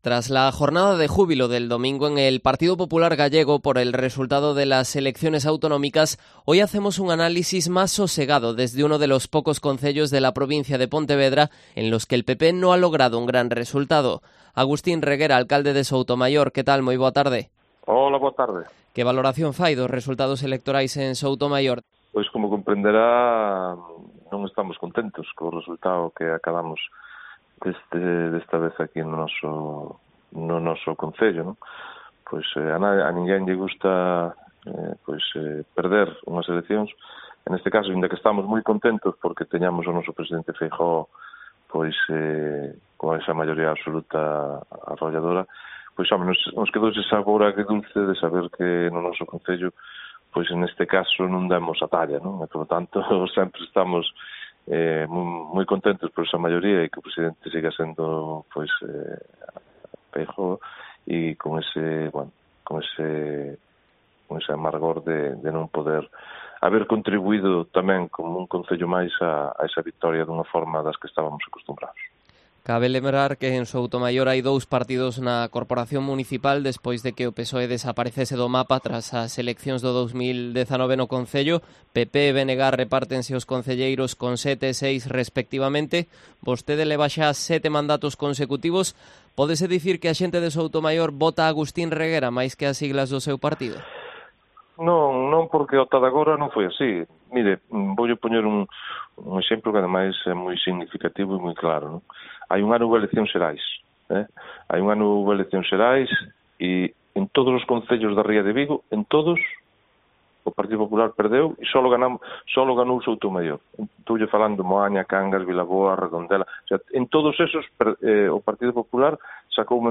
Entrevista al alcalde de Soutomaior, Agustín Reguera, en Cope Pontevedra